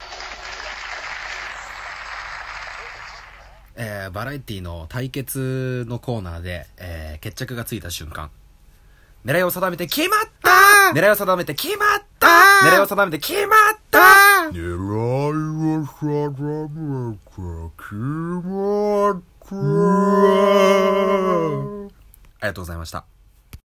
細かすぎて伝わらない声真似選手権